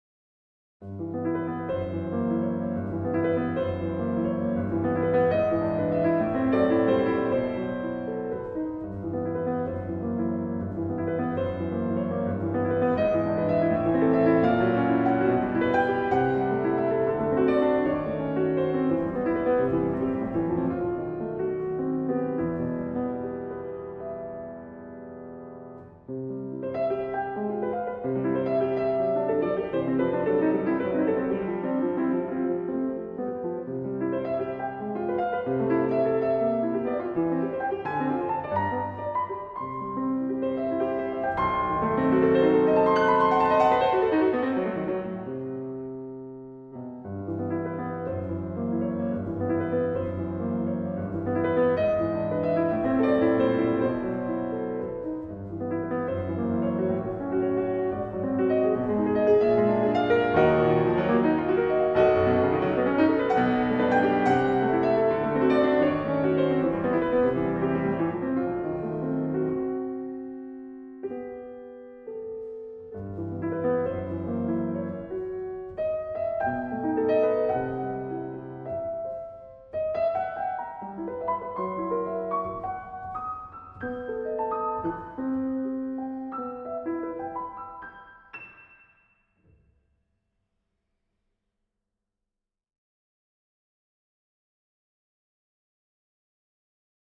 Enregistrement à la Salle Tibor Varga, Sion
Pièces pour piano.
N°1 la majeur (A major) Allegro
N°2 mi mineur (E minor) Allegretto